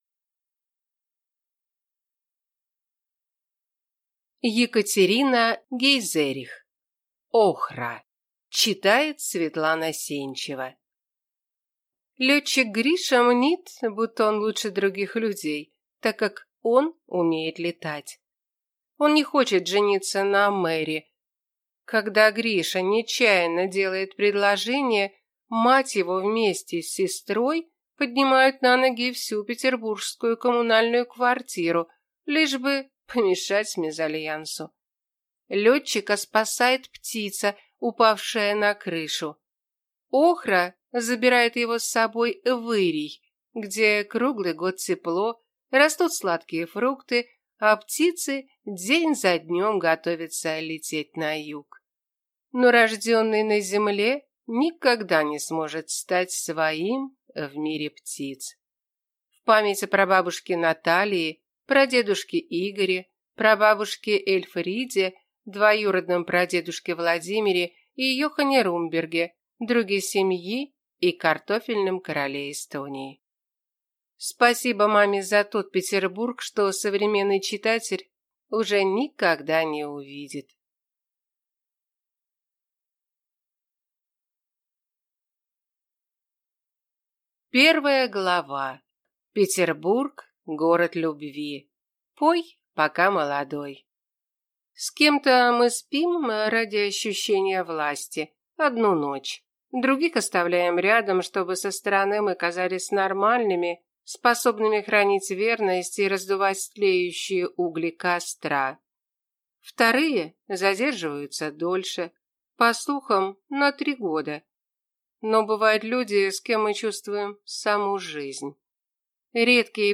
Аудиокнига Охра | Библиотека аудиокниг